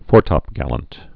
(fôrtŏp-gălənt, -təp-, -tə-)